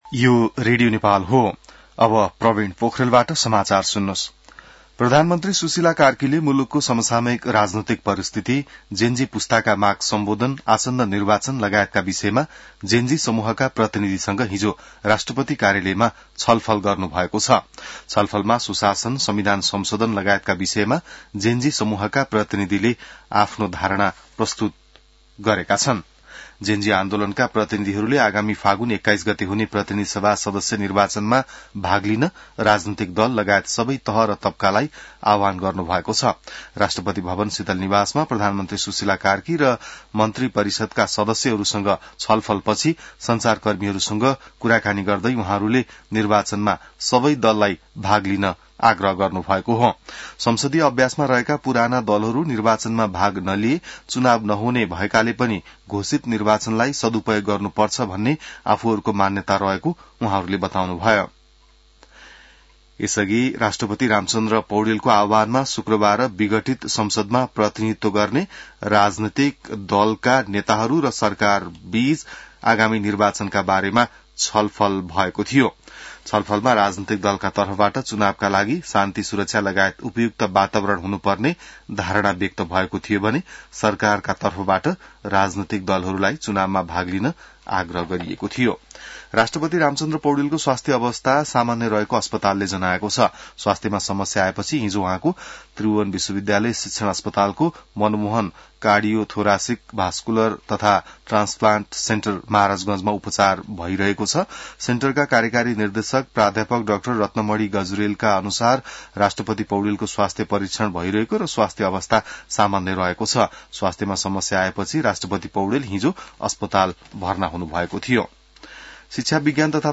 बिहान ६ बजेको नेपाली समाचार : २६ असोज , २०८२